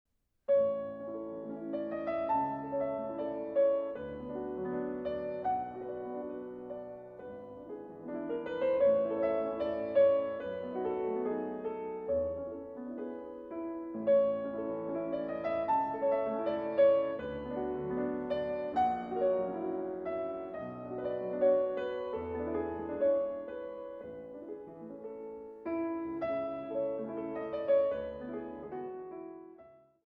g-moll Presto agitato